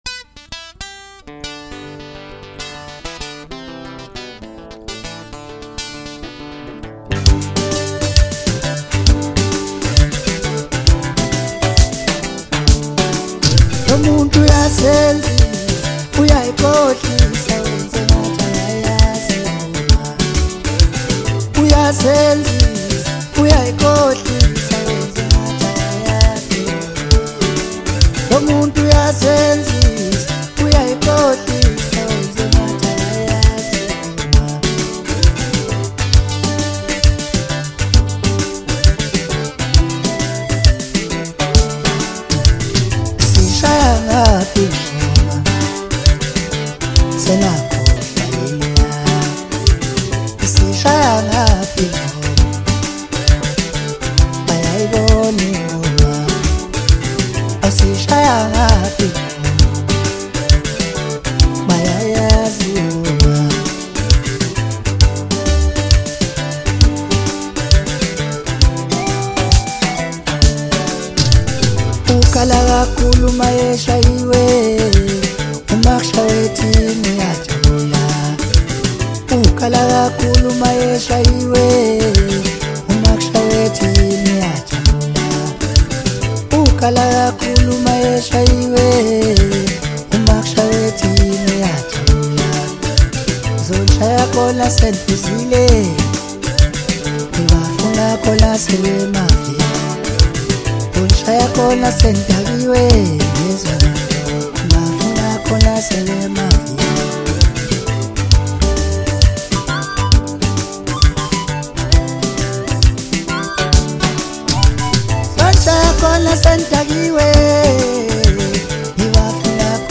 Zulu Maskandi